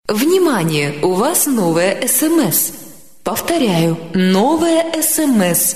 короткие
на смс
голосовые
Голосовое оповещение